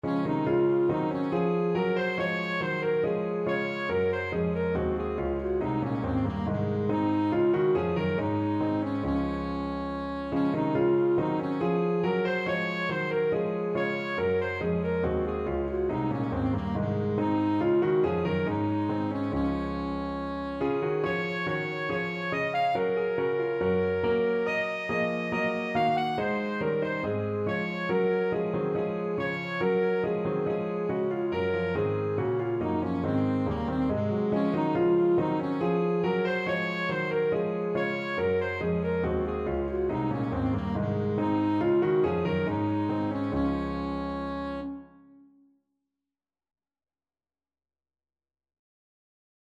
Alto Saxophone version
4/4 (View more 4/4 Music)
Saxophone  (View more Easy Saxophone Music)
Classical (View more Classical Saxophone Music)